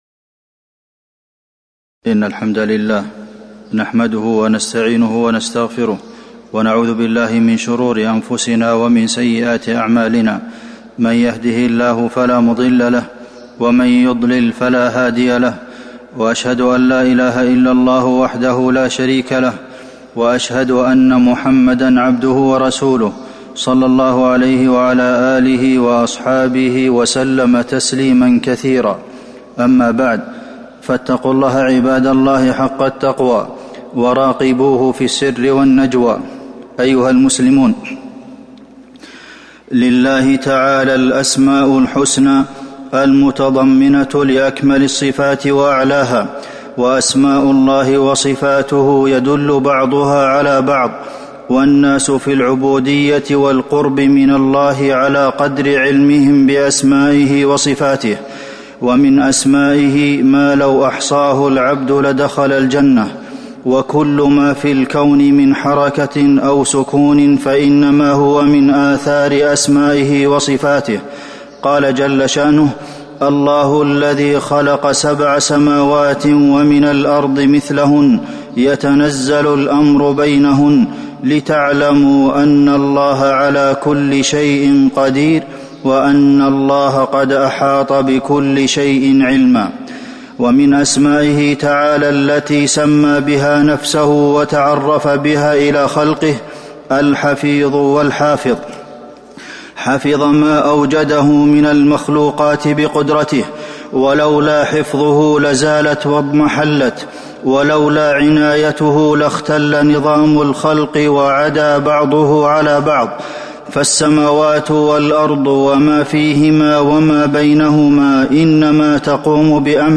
تاريخ النشر ١ شعبان ١٤٤٣ هـ المكان: المسجد النبوي الشيخ: فضيلة الشيخ د. عبدالمحسن بن محمد القاسم فضيلة الشيخ د. عبدالمحسن بن محمد القاسم الحفيظ